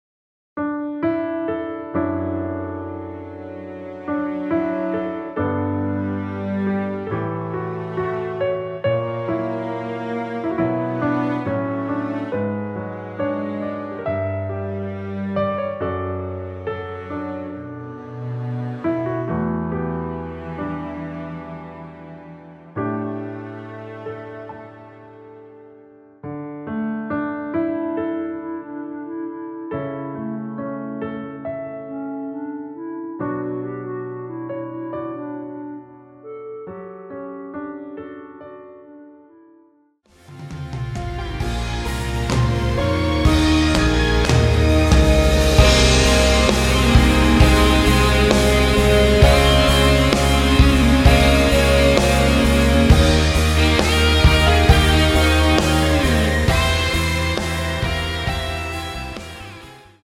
원키에서(-9)내린 멜로디 포함된 MR입니다.
앞부분30초, 뒷부분30초씩 편집해서 올려 드리고 있습니다.
중간에 음이 끈어지고 다시 나오는 이유는